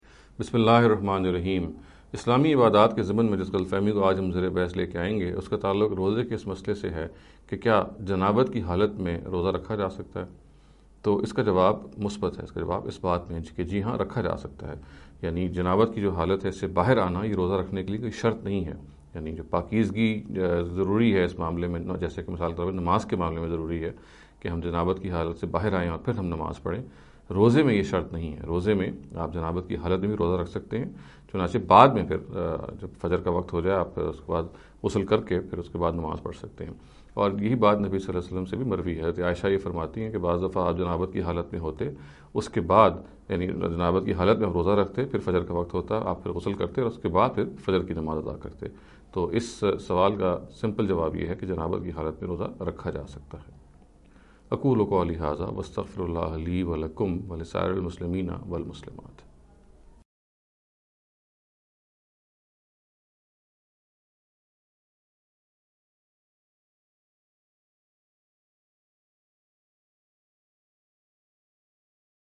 This lecture series will deal with some misconception regarding the Islamic Worship Ritual. In every lecture he will be dealing with a question in a short and very concise manner.